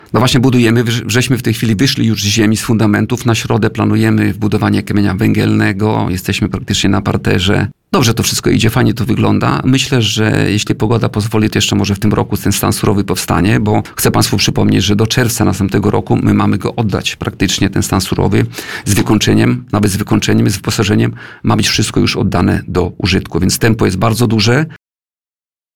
Starosta kolneński Tadeusz Klama ocenił na antenie Radia Nadzieja, że trwająca proces budowy Zakładu Opiekuńczo-Leczniczego w Szpitalu Ogólnym w Kolnie przebiega bez zakłóceń.